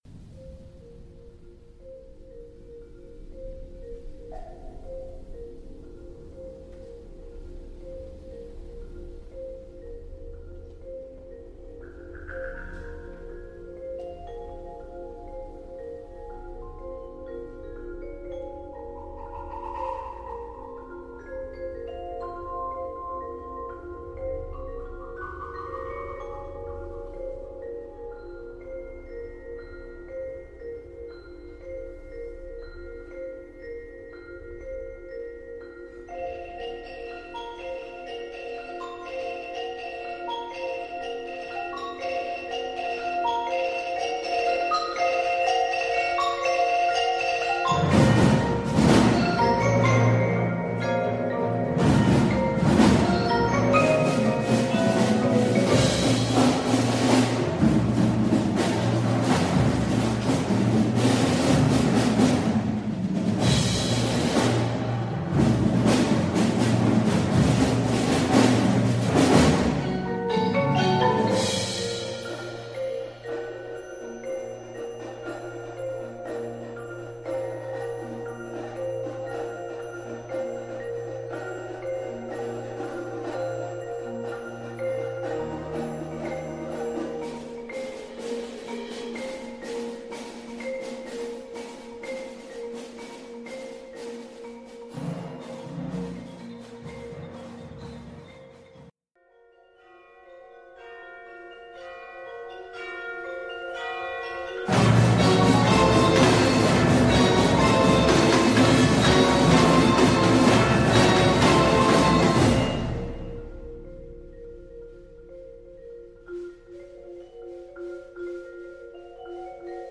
Genre Concert & Contest